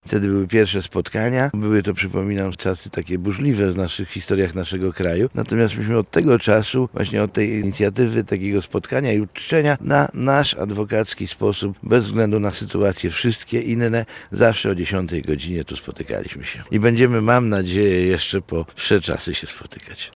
Przedstawiciele palestry spotkali się przy Pomniku Legionistów na cmentarzu przy ulicy Białej.